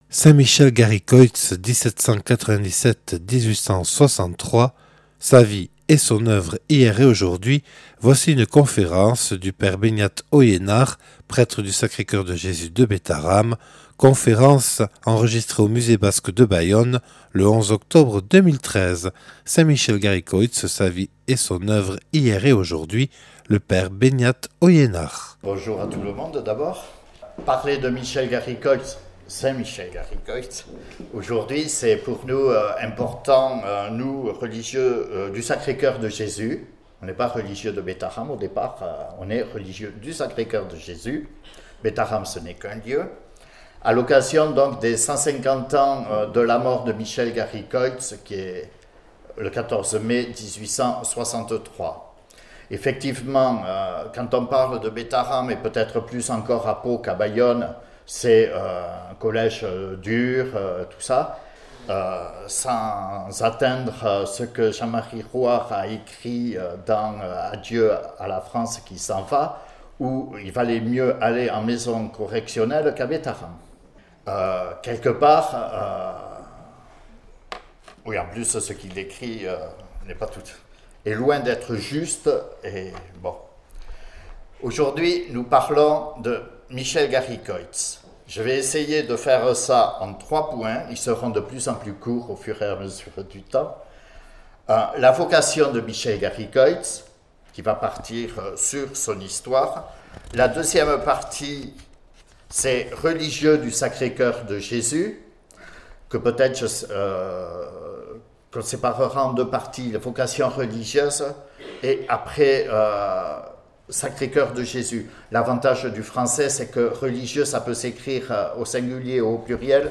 (Enregistrée le 11/10/2013 au Musée Basque de Bayonne).